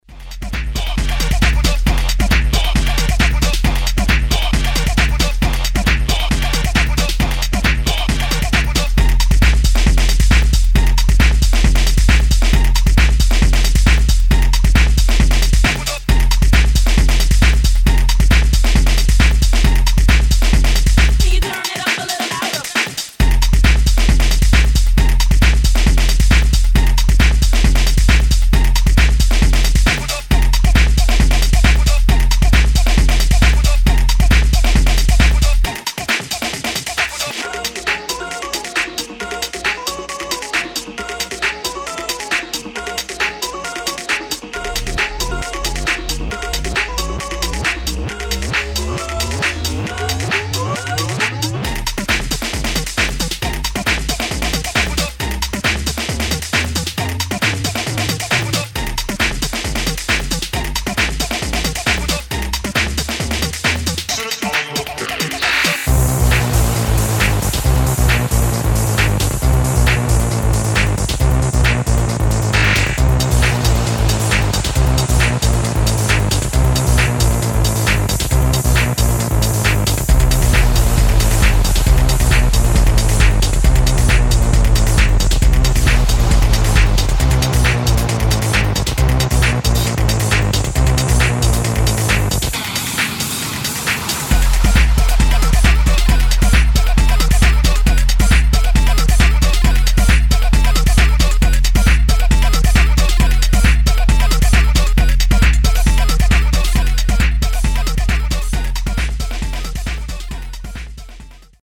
Techno Acid